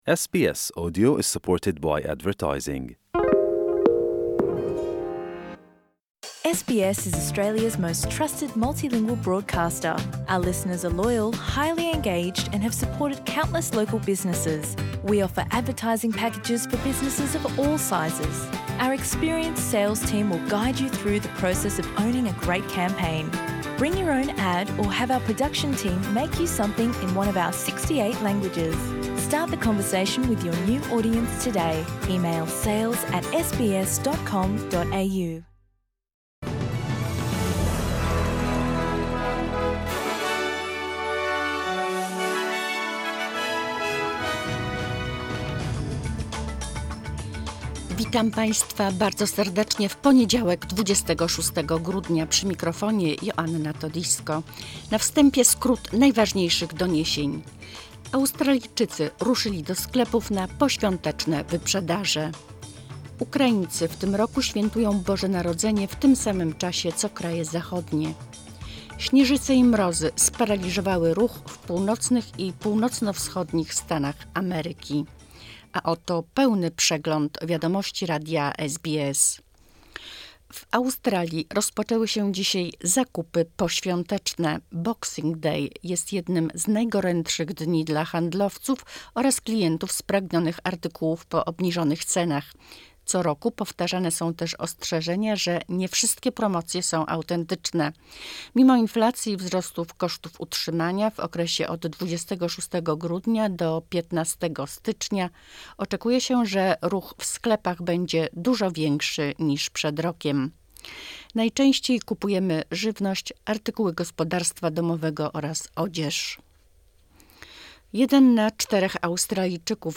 Wiadomości SBS 26 grudnia SBS